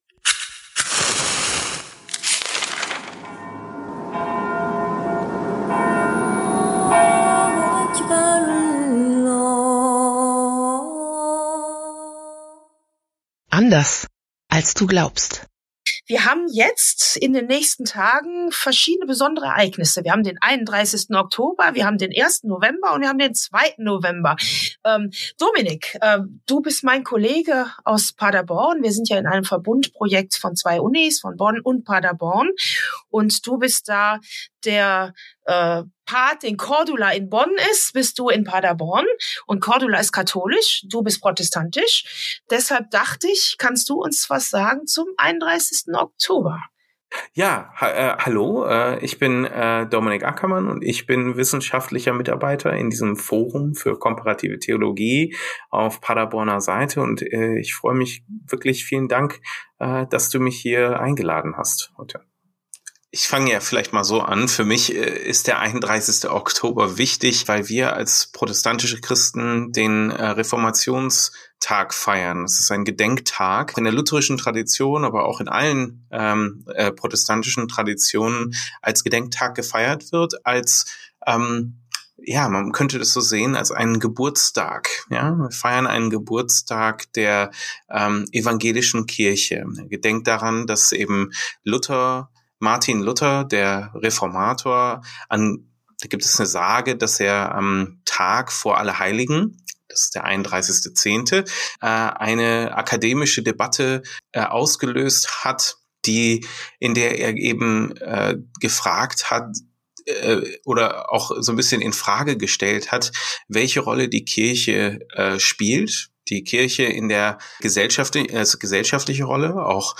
Ein evangelisch-katholisches Gespräch